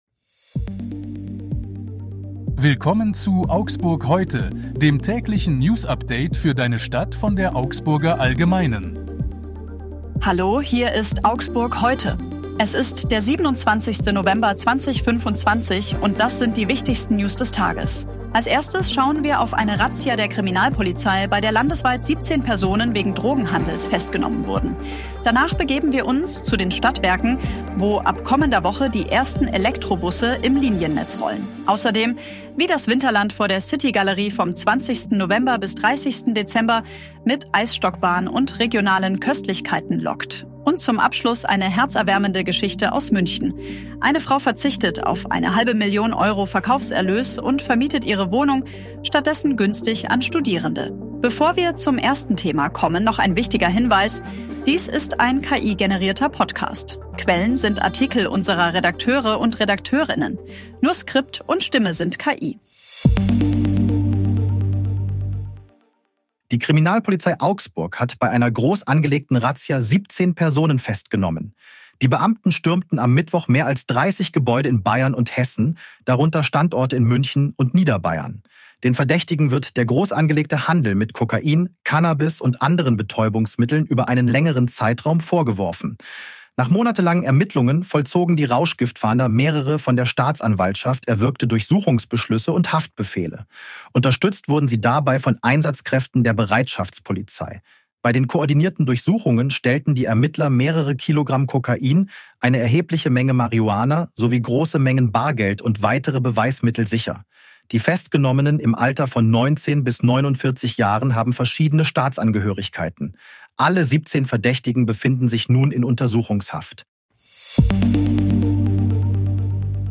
Hier ist das tägliche Newsupdate für deine Stadt.
Nur Skript und Stimme sind KI.